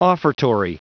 Prononciation du mot offertory en anglais (fichier audio)
offertory.wav